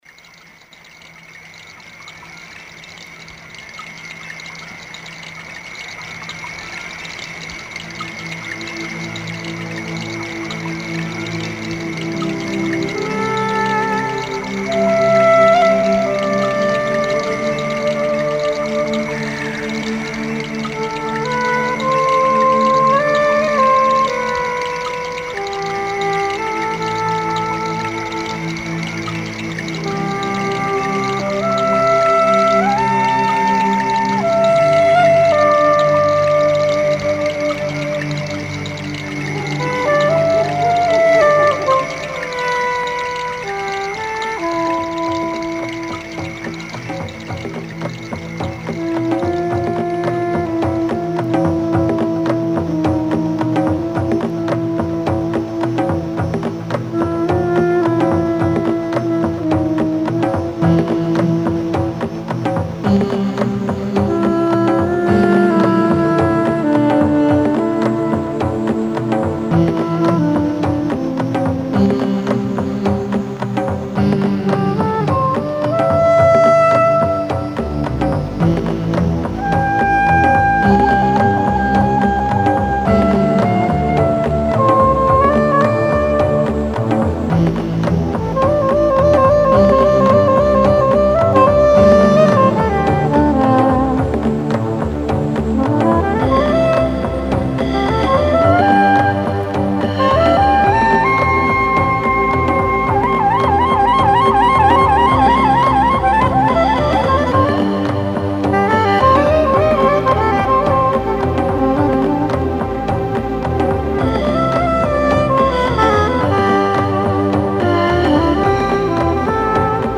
Sax soprano